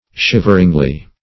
shiveringly - definition of shiveringly - synonyms, pronunciation, spelling from Free Dictionary Search Result for " shiveringly" : The Collaborative International Dictionary of English v.0.48: Shiveringly \Shiv"er*ing*ly\, adv. In a shivering manner.